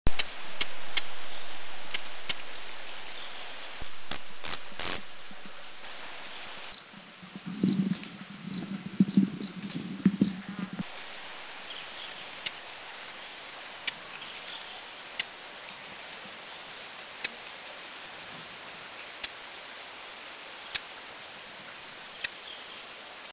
Russet Bush Warbler